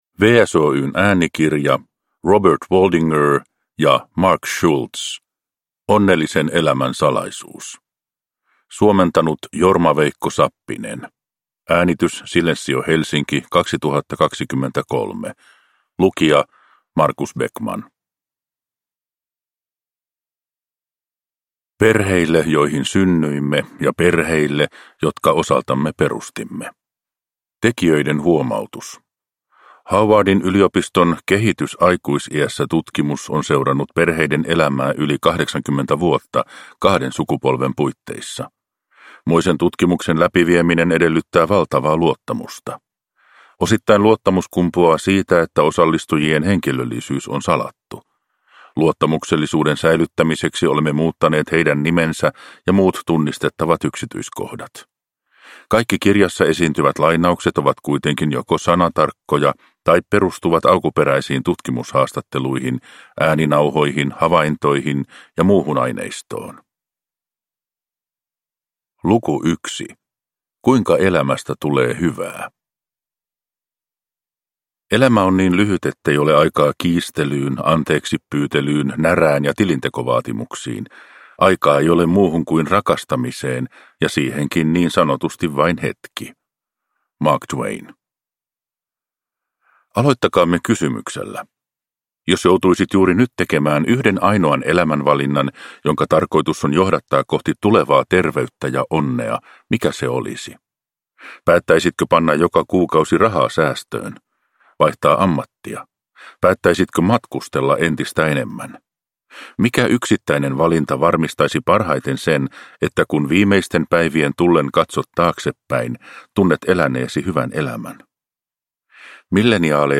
Onnellisen elämän salaisuus – Ljudbok – Laddas ner